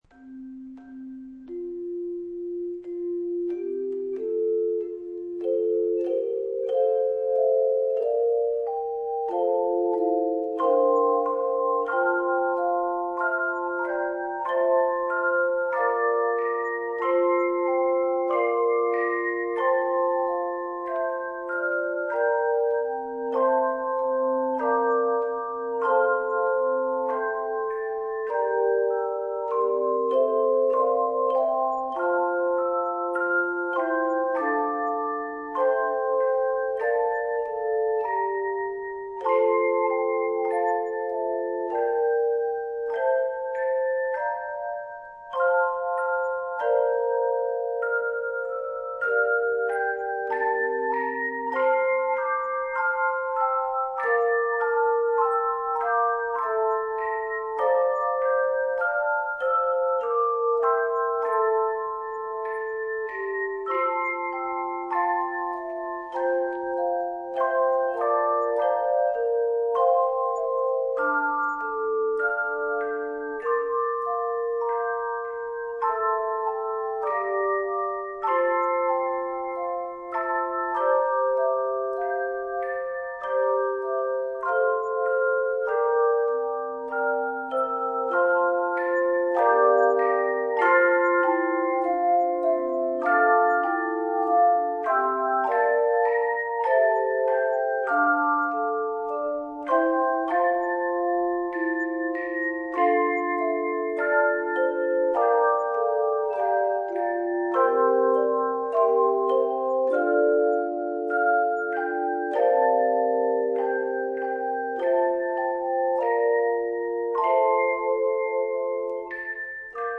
Voicing: Handbells 2-5 Octave